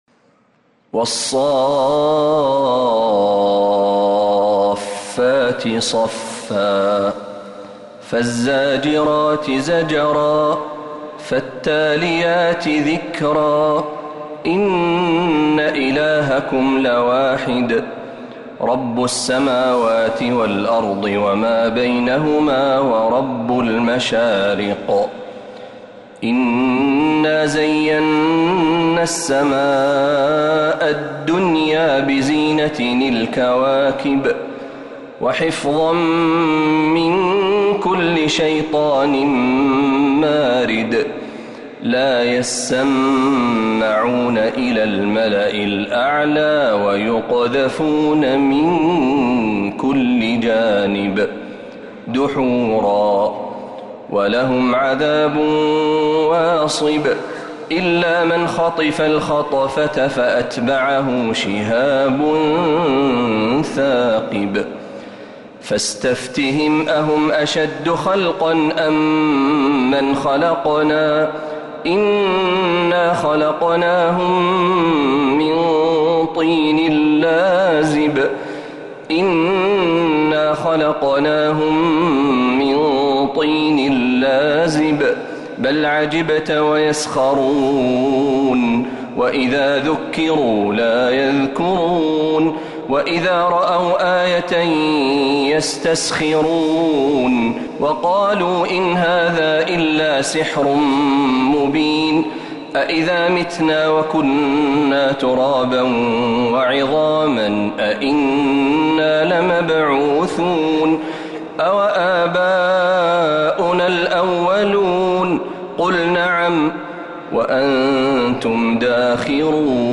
سورة الصافات كاملة من الحرم النبوي | رمضان 1446هـ